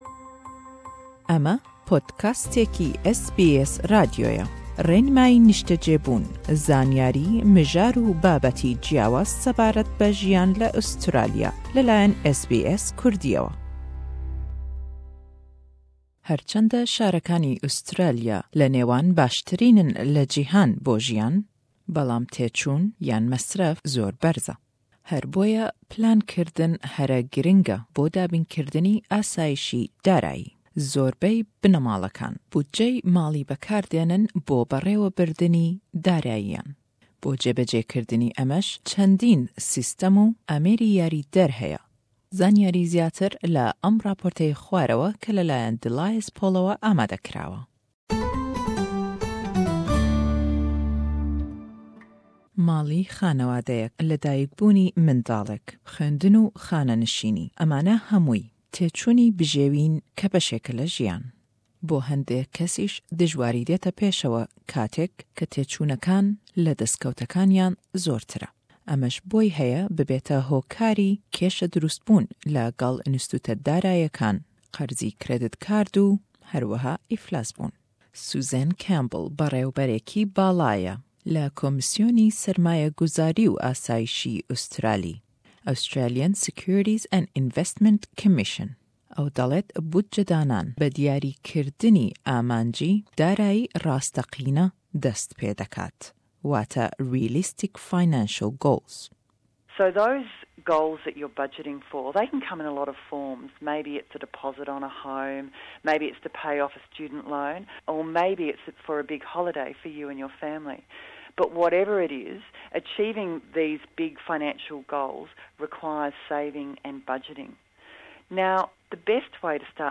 Plankirna siberojê ji bo ewlekariya aborî giring e. Gelek malbat bucekirinê li mal ji bo birêvebirina abûrî pêk tînin. Raport bi Kurdî/Îngilîzî.